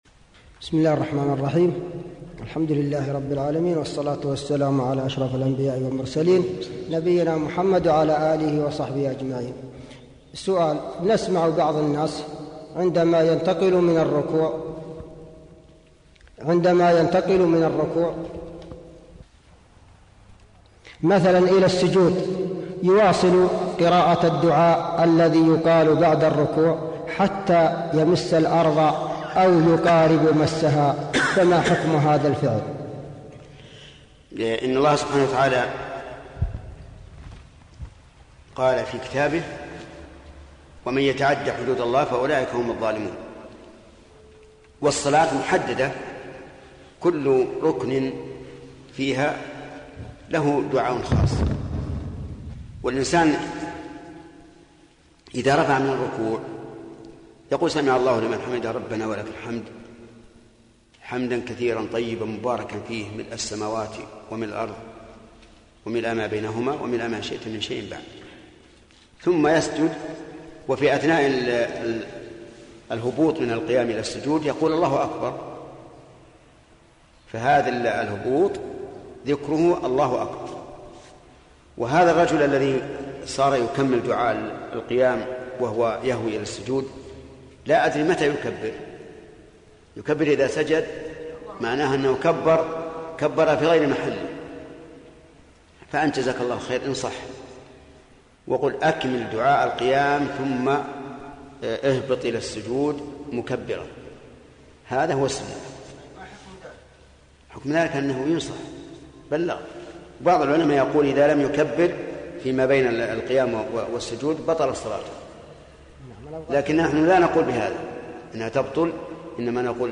المصدر: سلسلة لقاءات الباب المفتوح > لقاء الباب المفتوح [111]